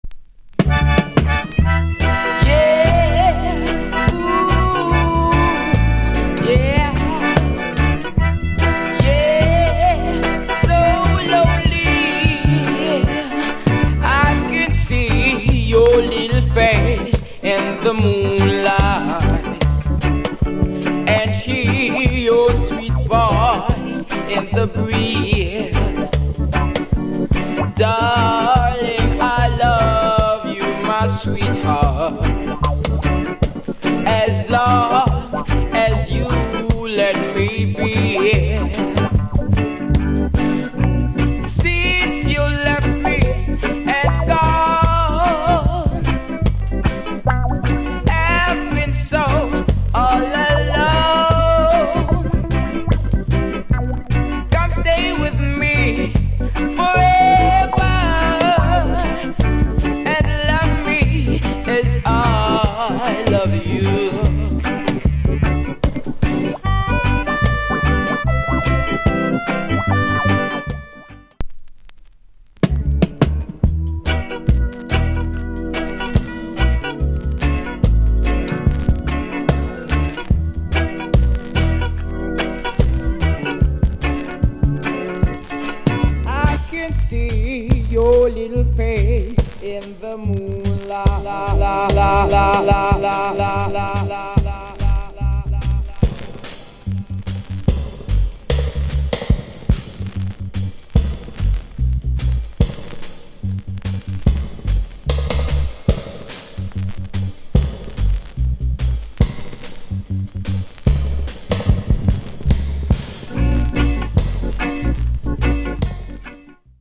* Good Vocal.